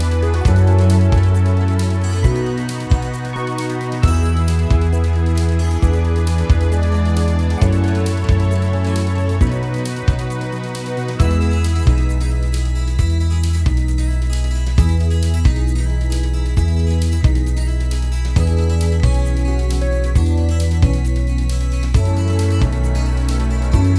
For Solo Female Pop (1990s) 3:36 Buy £1.50